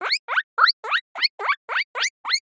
animal
Guinea Pig Squeak 1